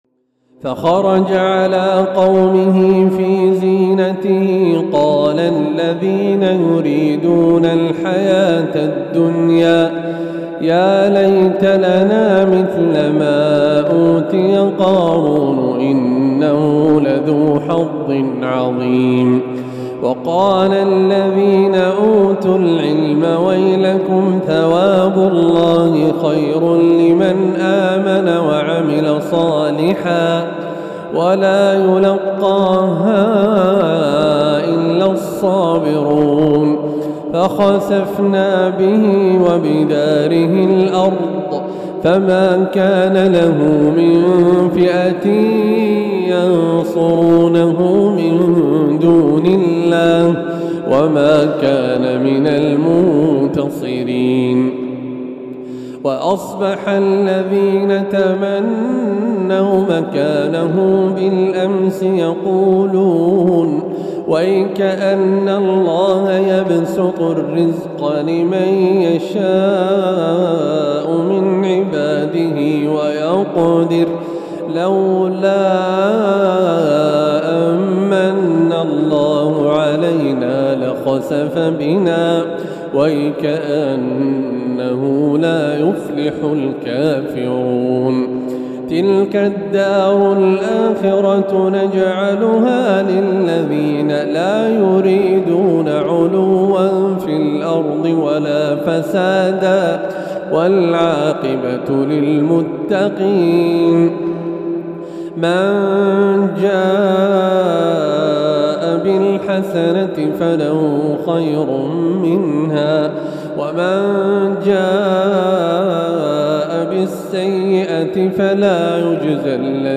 تلاوة مميزة
تلاوة خاشعة هادئة من سورة القصص